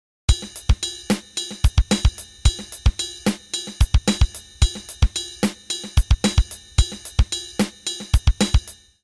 Here’s a great funky groove from Herman Matthews of Tower of Power fame.
1 + 2 + 3 + 4 + (1/4 = 110 bpm) RB x x x x SD o o O o O KD o o oo o HH x x x x It's a simple pattern, and Matthews makes it feel great.